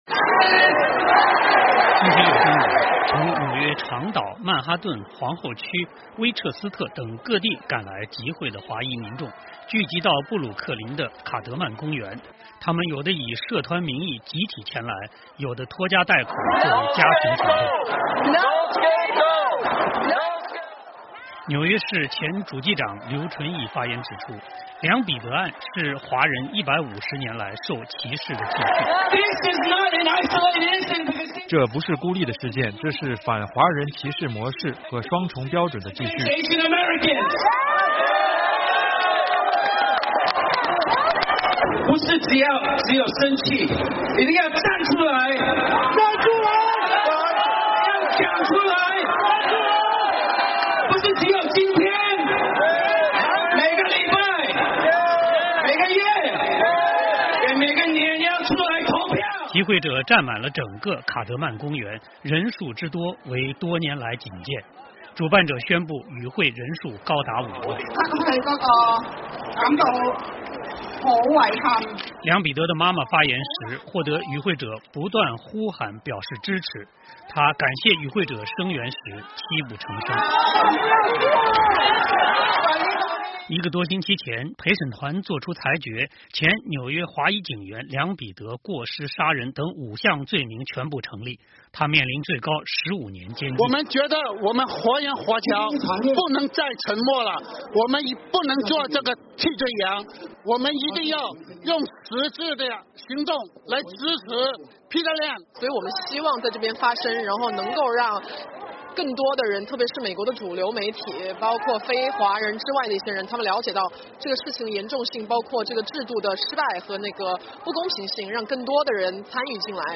纽约以华裔为主的数万民众集合，他们高呼“不做替罪羊”“要公平”等口号；现任、前任民选官员也纷纷前来表态支持，他们有的认为该案凸显司法制度的失败，有的强调这是150年华裔受歧视历史的继续，有的则对纽约警察冒生命危险执法表示支持。